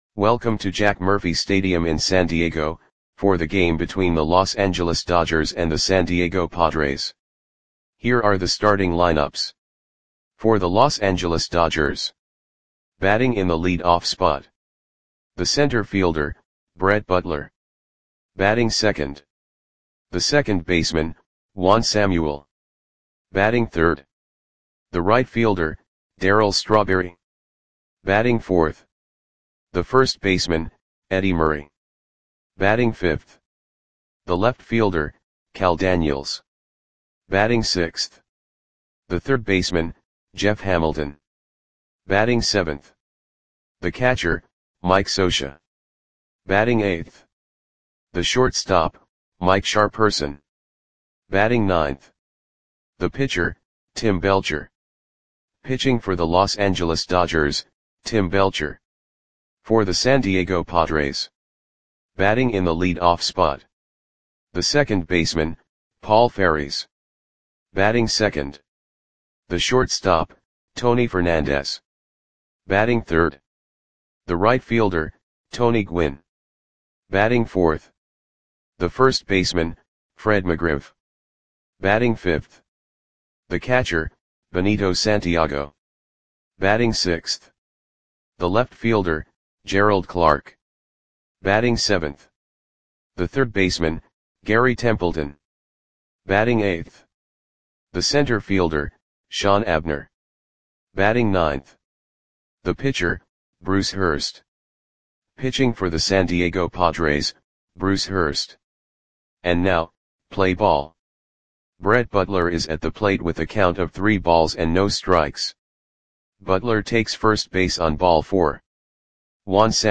Audio Play-by-Play for San Diego Padres on April 20, 1991
Click the button below to listen to the audio play-by-play.